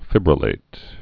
(fĭbrə-lāt, fībrə-)